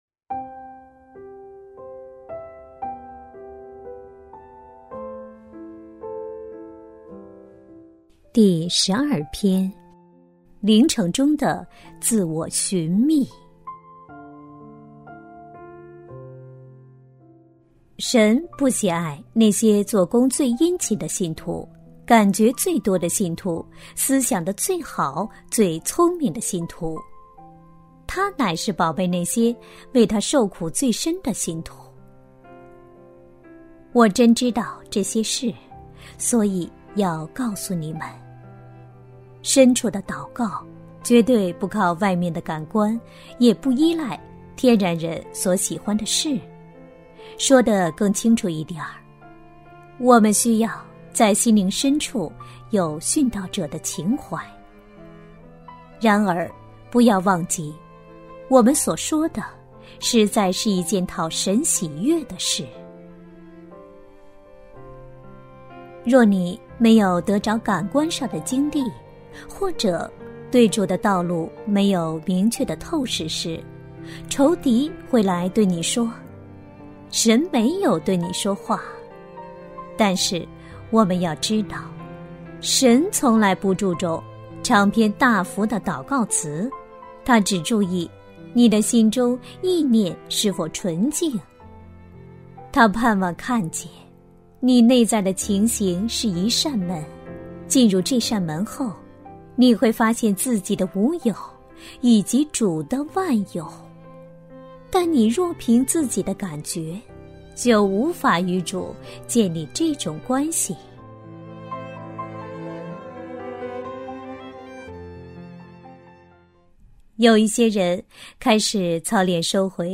首页 > 有声书 | 灵性生活 | 灵程指引 > 灵程指引 第十二篇：灵程中的“自我寻觅”